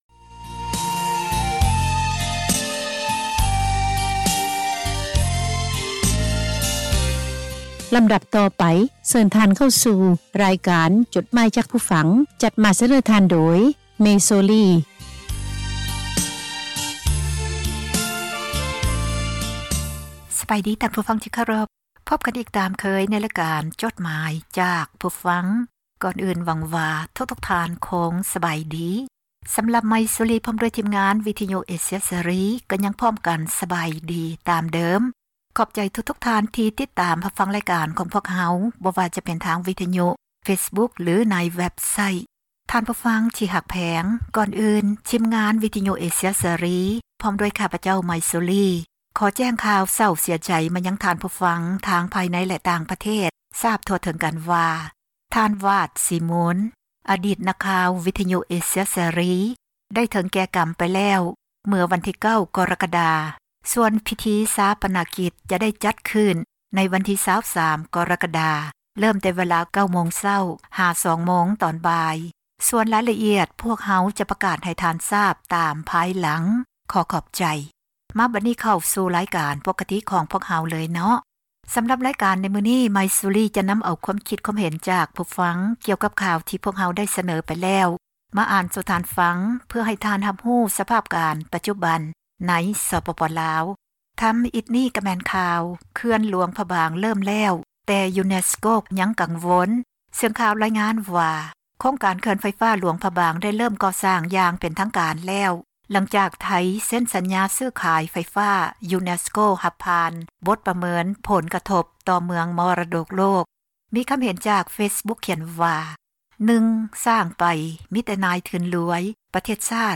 ຢູແນັສໂກ ຍັງກັງວົນ ຜົລກະທົບ ຈາກເຂື່ອນຫຼວງພຣະບາງ: ຈົດໝາຍ ຈາກຜູ້ຟັງ ອ່ານຈົດໝາຍ, ຄວາມຄຶດຄວາມເຫັນ ຂອງທ່ານ ສູ່ກັນຟັງ ເພື່ອເຜີຍແຜ່ ທັສນະ, ແນວຄິດ ທີ່ສ້າງສັນ, ແບ່ງປັນ ຄວາມຮູ້ ສູ່ກັນຟັງ.